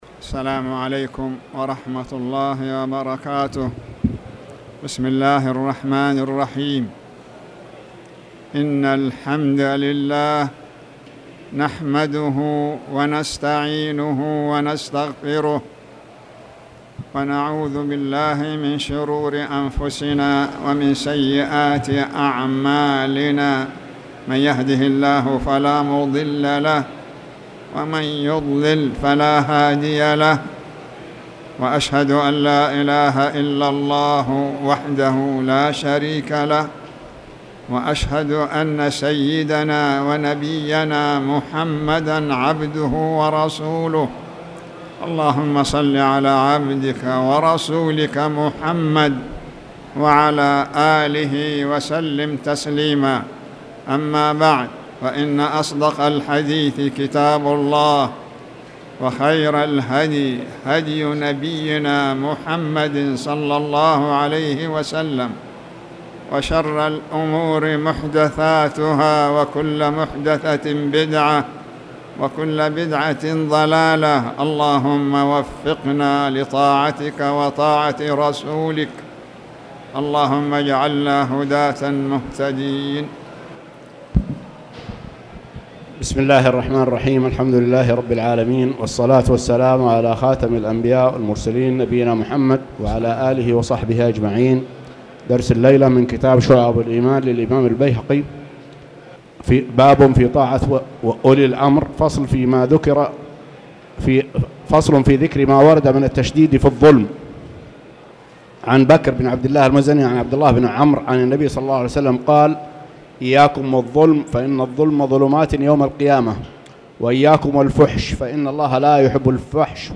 تاريخ النشر ٢٤ محرم ١٤٣٨ هـ المكان: المسجد الحرام الشيخ